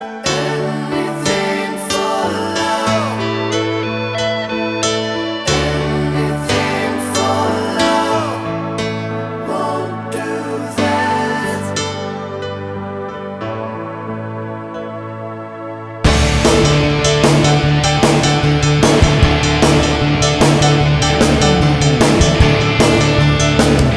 Duet Rock 5:27 Buy £1.50